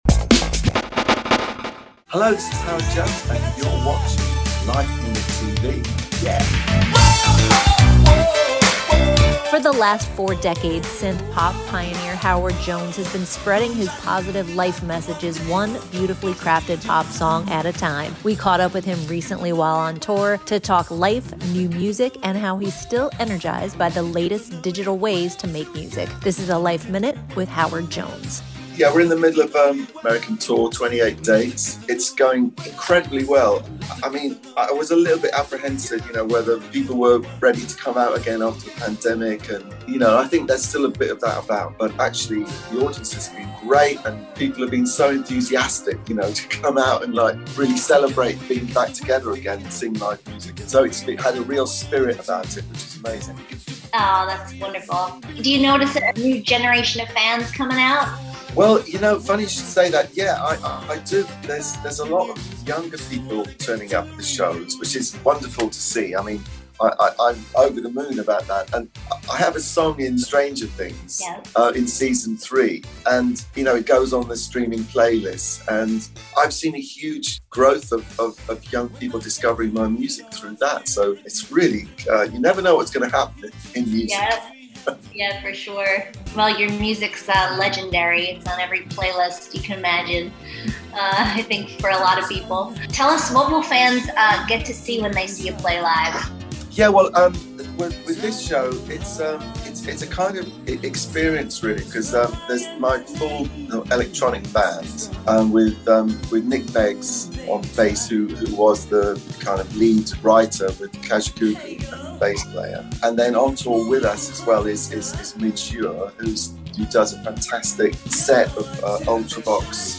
We sat down with Jones recently to discuss his current tour with Midge Ure of Ultravox and Kajagoogoo's, Nick Beggs, and his new album, Dialogue. Much of the new music was conceived during Covid lockdown, but the lyrical content came afterward for fear of being a little too downbeat for his style.